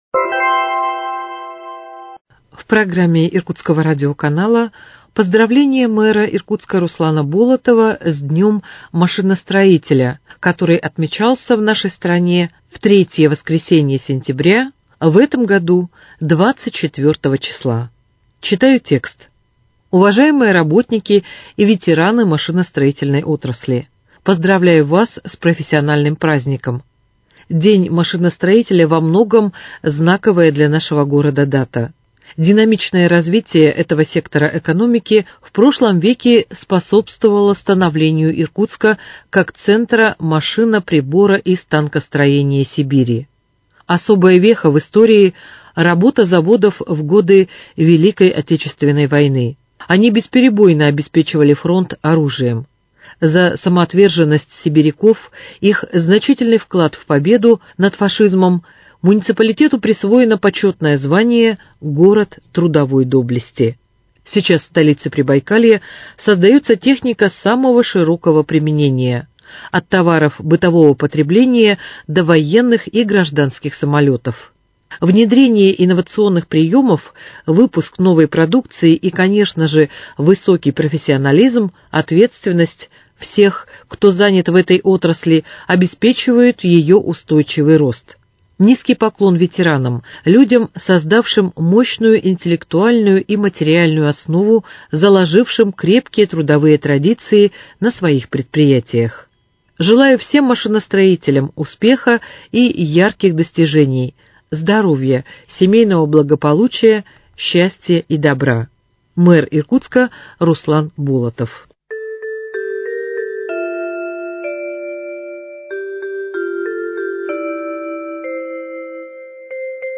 Поздравление с Днем машиностроителя от мэра Иркутска Руслана Николаевича Болотова